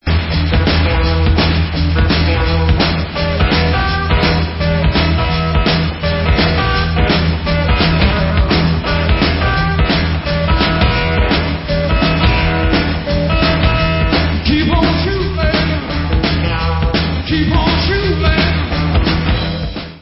Remasterovaná verze živého alba z roku 1970